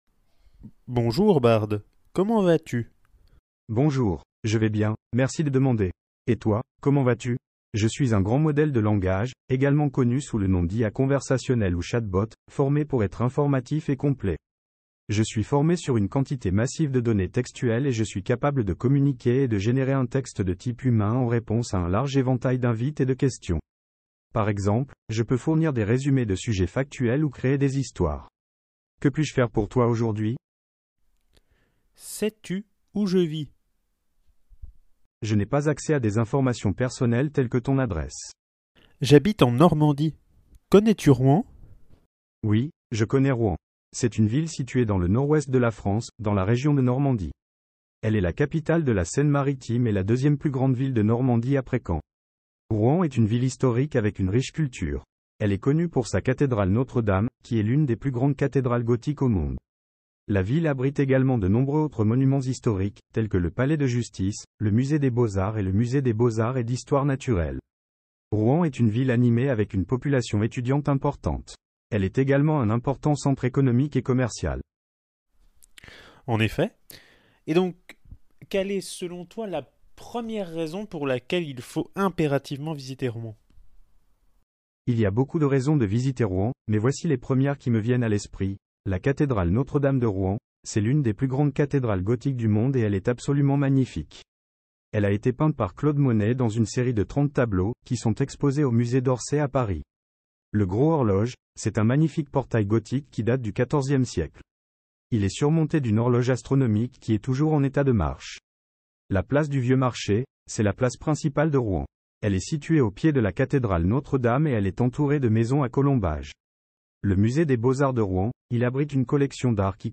Interview avec une intelligence artificielle !